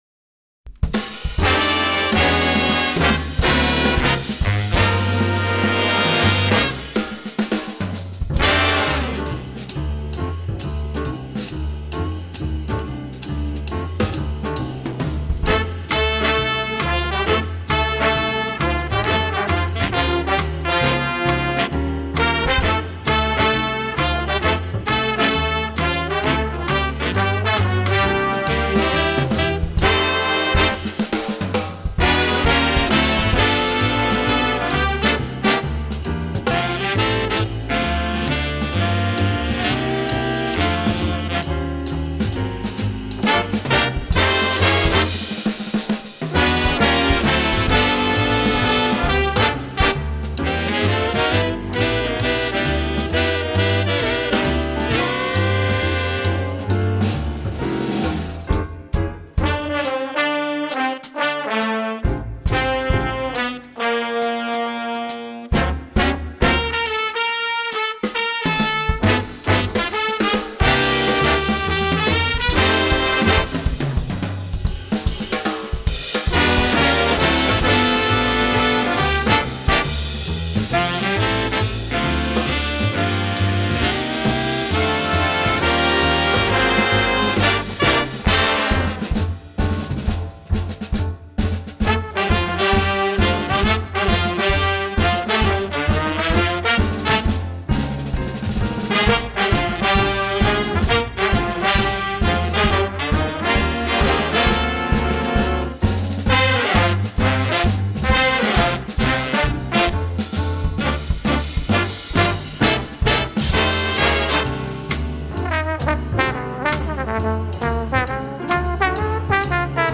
Voicing: Jazz Band